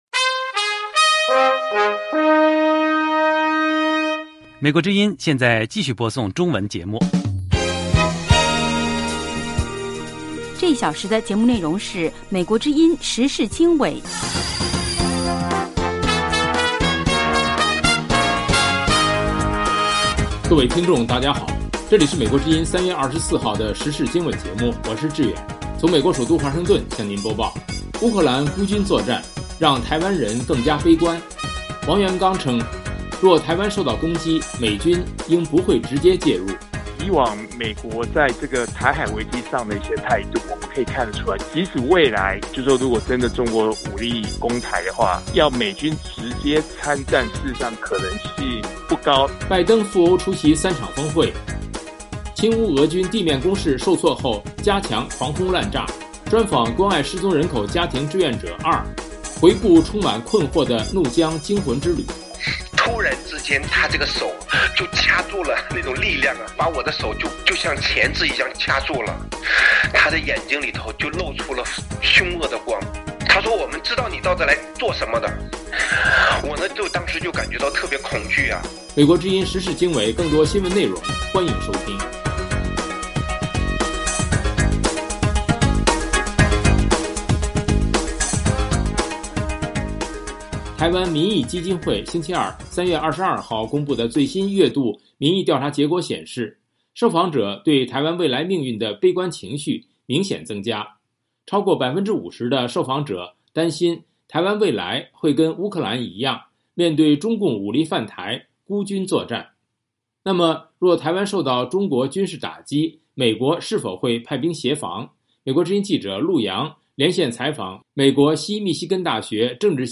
2/专访关爱失踪人口家庭志愿者（2）：回顾充满困惑的怒江惊魂之旅。